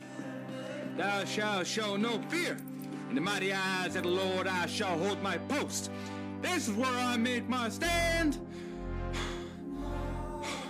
this is where i make my stand Meme Sound Effect
Category: Games Soundboard